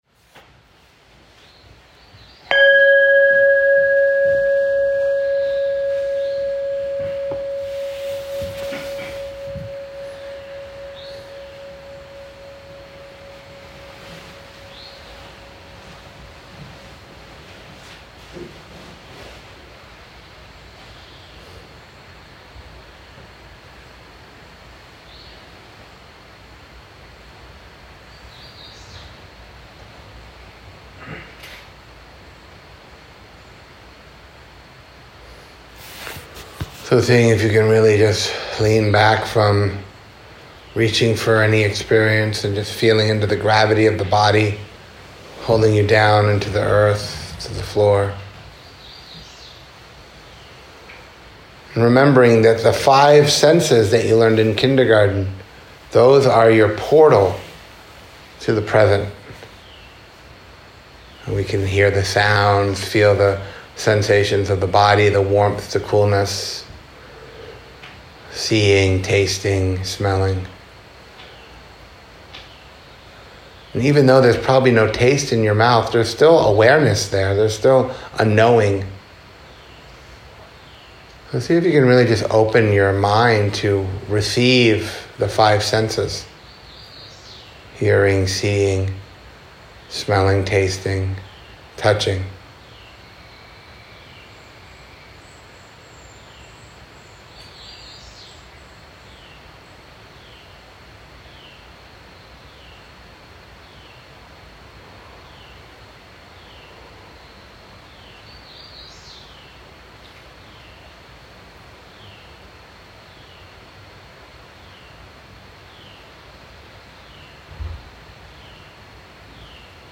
They are edited together here for your convenience to offer a comprehensive overview. Here, the first 3 are described: existential, therapeutic, and contemplative.